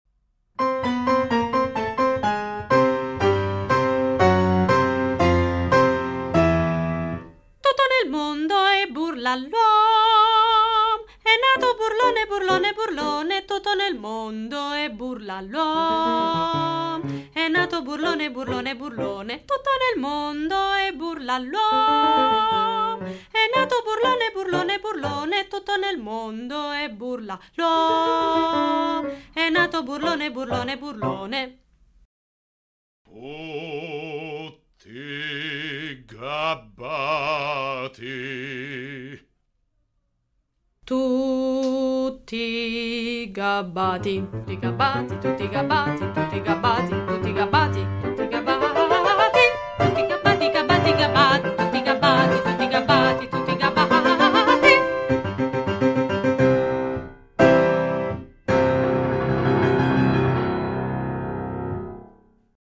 Tutto nel mondo è burla! – Ritmica